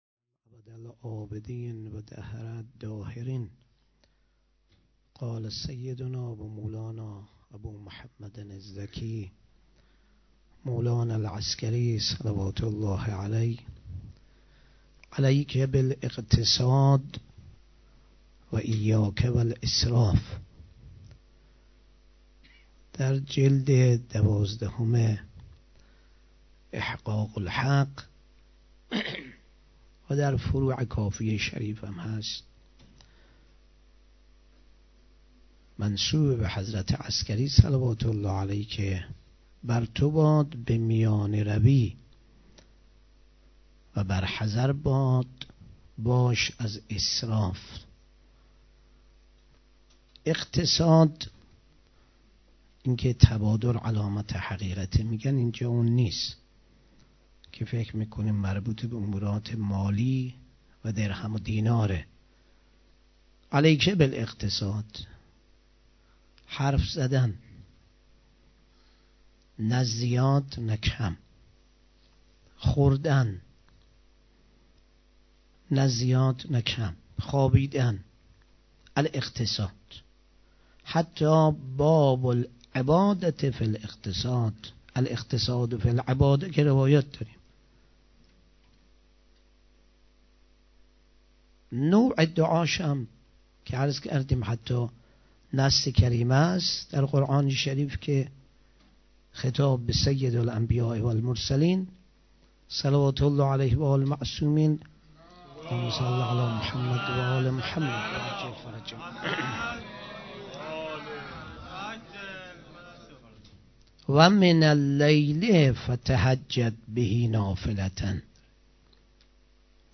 6 دی 96 - هیئت عسکریین - سخنرانی